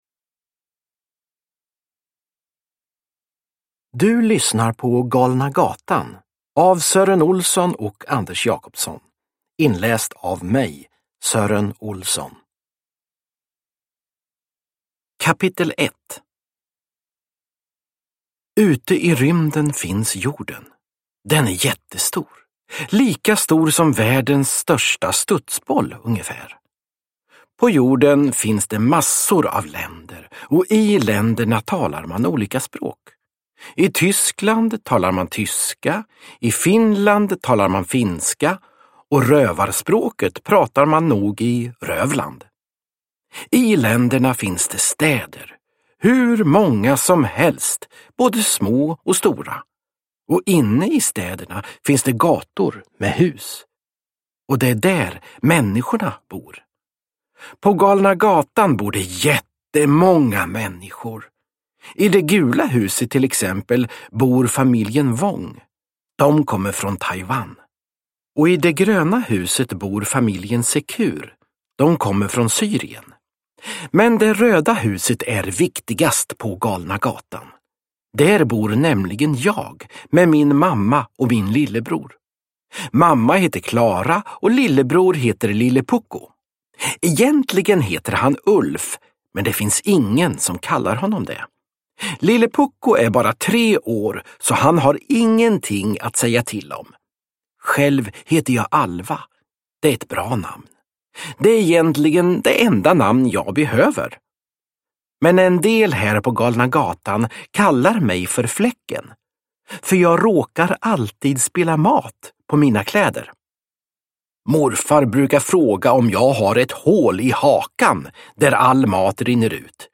Galna gatan – Ljudbok – Laddas ner